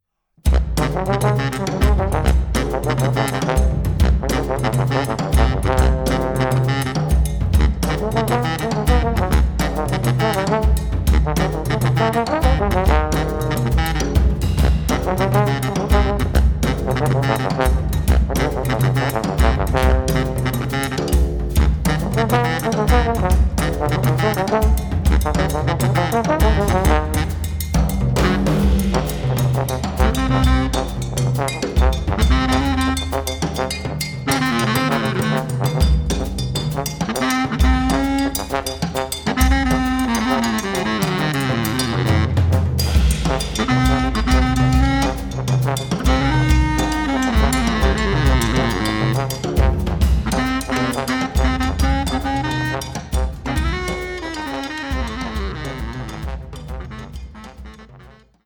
Posaune
Baritonsaxophon
Schlagzeug